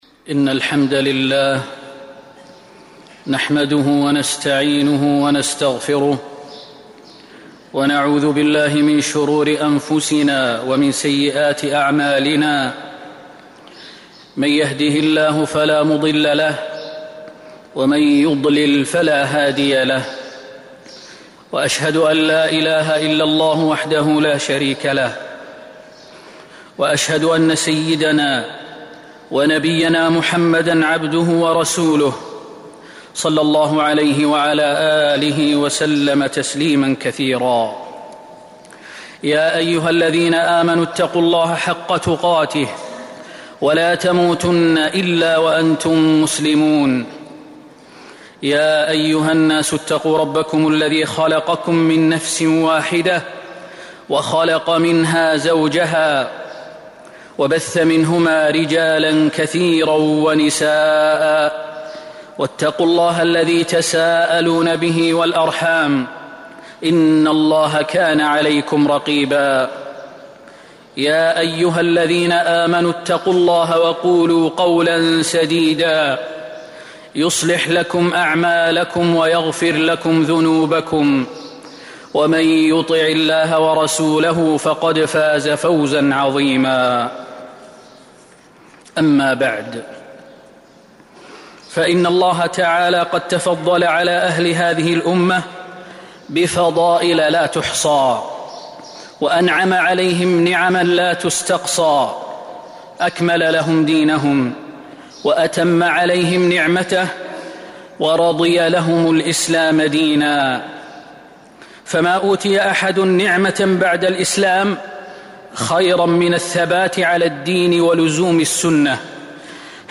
جودة عالية